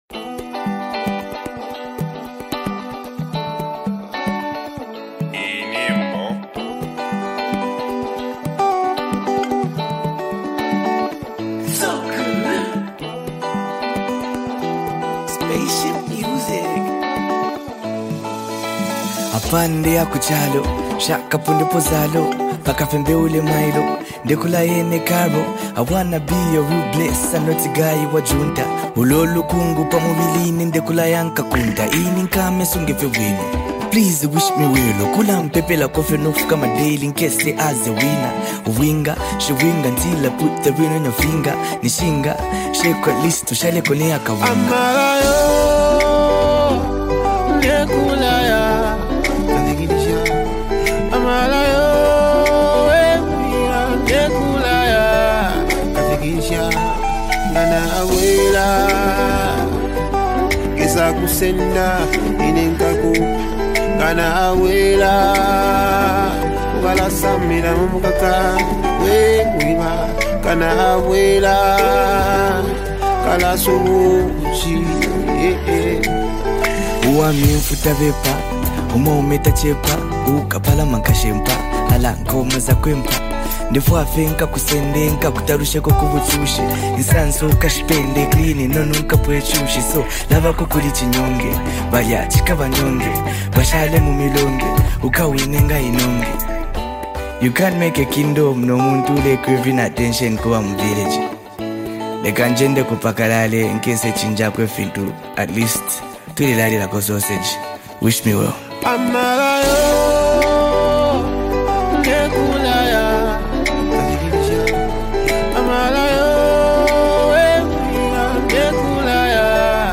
beautiful and heartfelt song